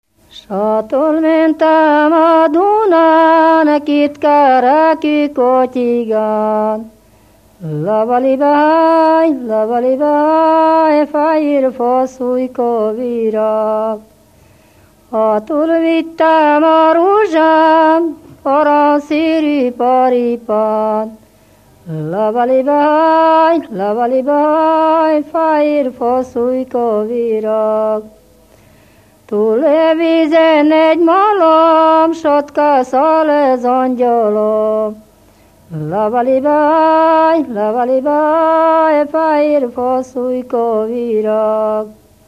Moldva és Bukovina - Moldva - Klézse
Kitelepülés helye: Egyházaskozár
Stílus: 8. Újszerű kisambitusú dallamok